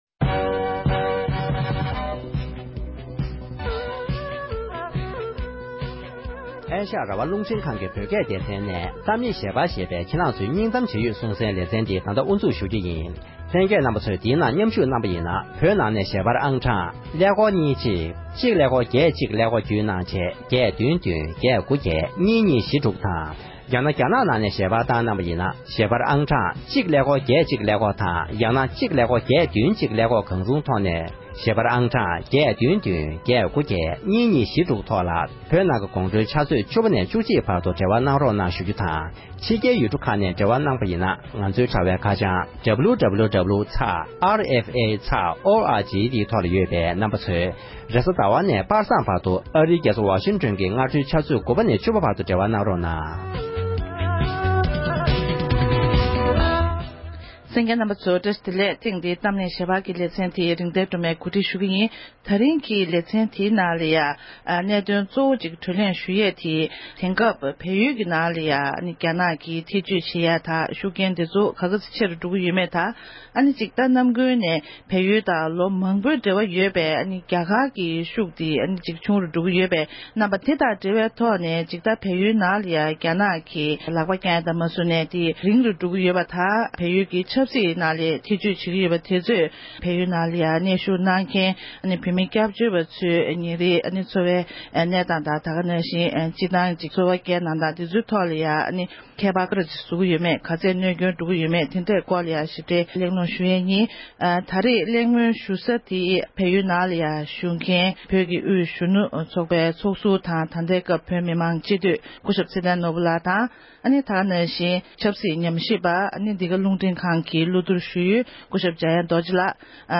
༄༅༎དེ་རིང་གི་གཏམ་གླེང་ཞལ་པར་གྱི་ལེ་ཚན་ནང་དུ་དེང་སྐབས་བལ་ཡུལ་གྱི་ནང་དུ་རྒྱ་ནག་གི་ཤུགས་རྐྱེན་ཆེ་རུ་འགྲོ་བཞིན་པ་དེས་སྤྱིར་རྒྱ་གར་གྱི་བདེ་འཇགས་ལ་ཉེན་ཁ་བཟོ་ཡི་ཡོད་པ་དང་།ཡང་སྒོས་བོད་མི་སྐྱབས་བཅོལ་བ་ཚོའི་མ་འོངས་མདུན་ལམ་ལ་དཀའ་ངལ་ཆེན་པོ་བཟོ་ཡི་ཡོད་པའི་སྐོར་